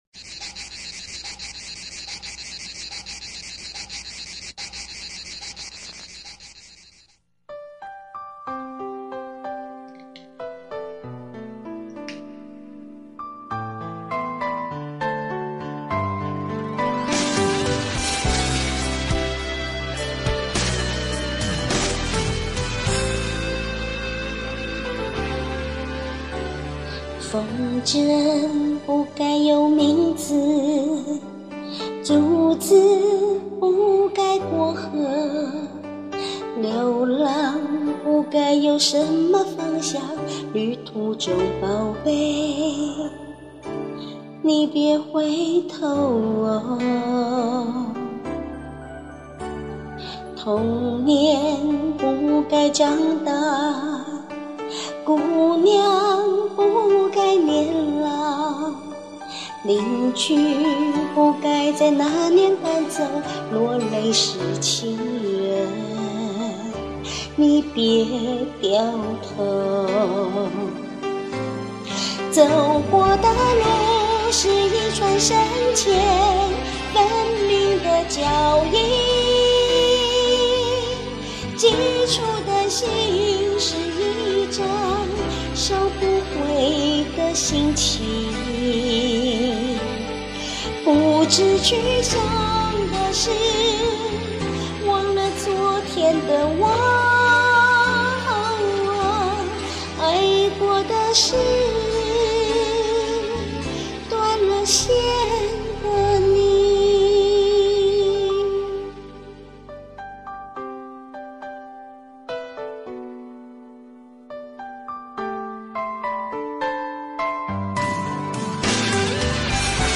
這裡又找到一首像貓叫的咆嘯